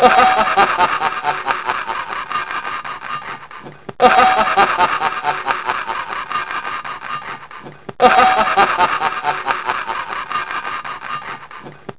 By popular demand (you asked for it) Me ! (laughing).
My-Laugh.wav